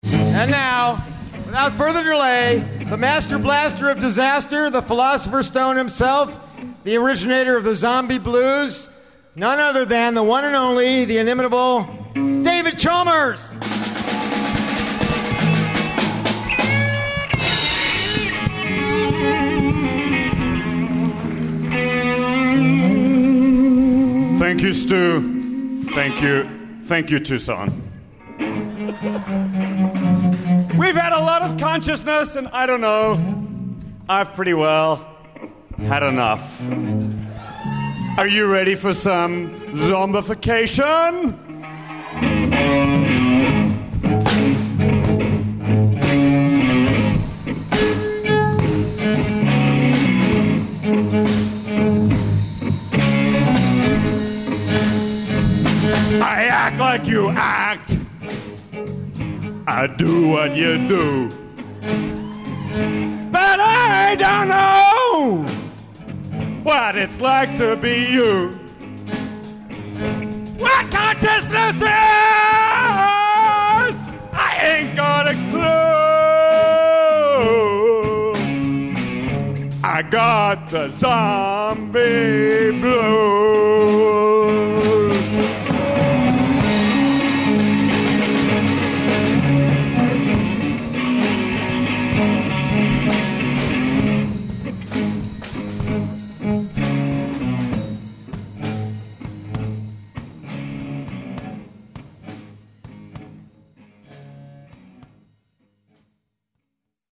The philosopher most closely associated with zombies presides by tradition over a Bacchic uproar called the Zombie Blues and Poetry Slam, marking the close of the University of Arizona’s biennial conference, Toward a Science of Consciousness .